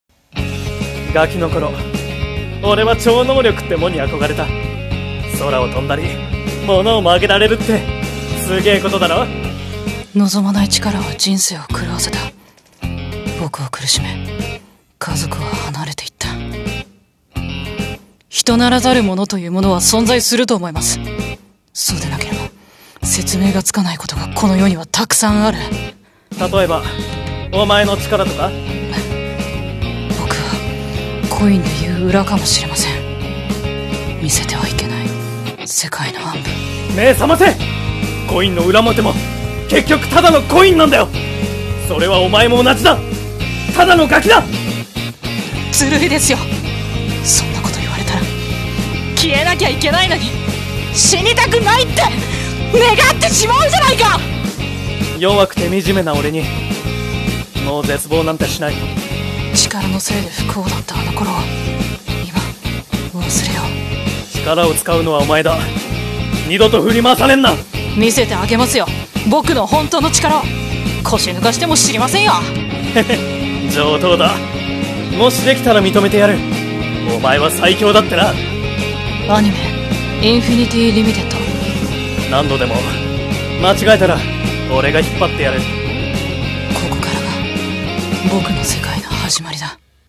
CM風声劇台本【INFINITY LIMITED】